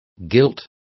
Complete with pronunciation of the translation of gilts.